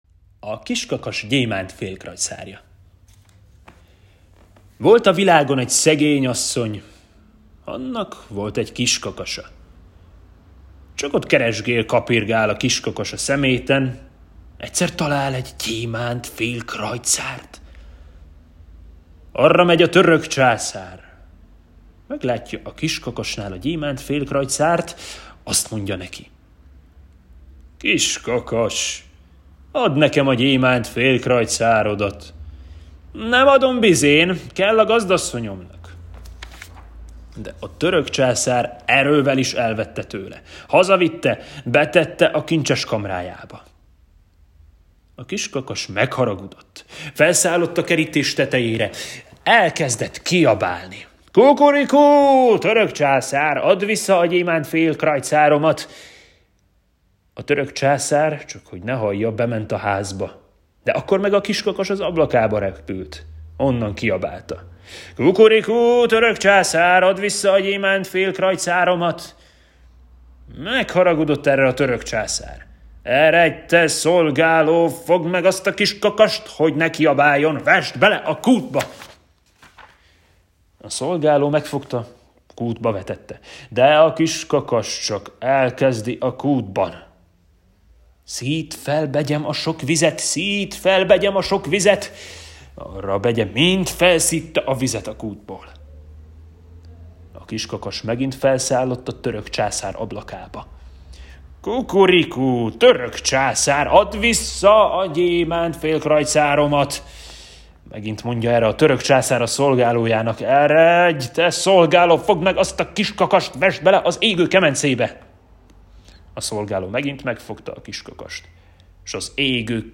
Hangos mesék